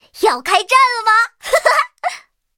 M4A3E2小飞象编入语音.OGG